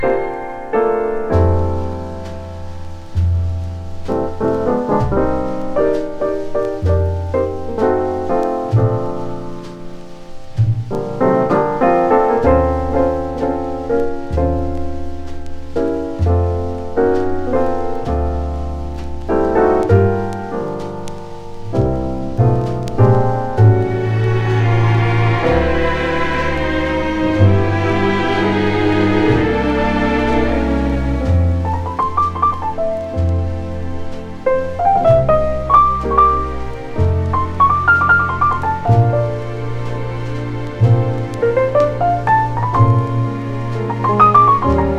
選曲も良く、静けさと贅沢なムードに包まれる1枚です。
Jazz, Easy Listening, Lounge　USA　12inchレコード　33rpm　Stereo